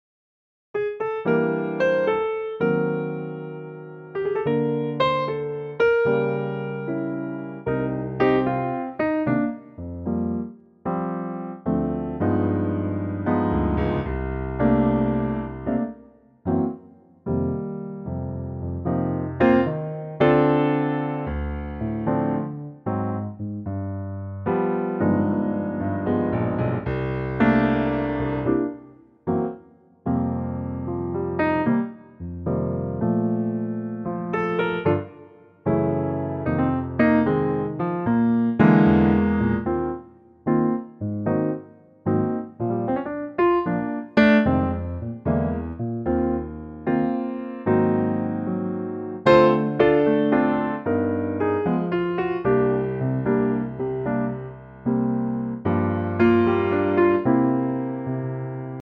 key - Bb - vocal range - F to G
Here's a piano only arrangement.